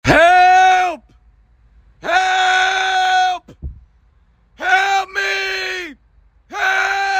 Bass Boosted Help Me Sound Effect Free Download
Bass Boosted Help Me